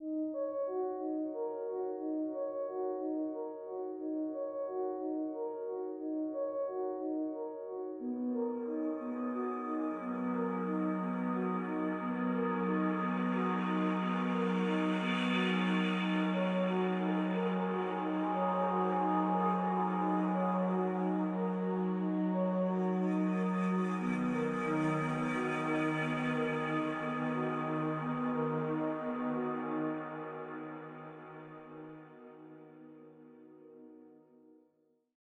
Added Ambient music pack.